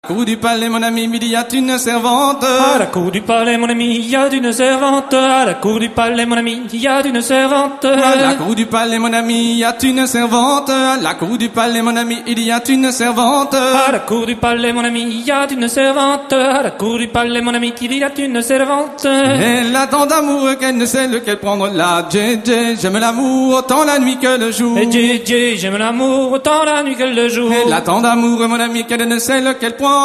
Musique : Traditionnel
Origine : Bretagne
Danse : Rond de Loudéac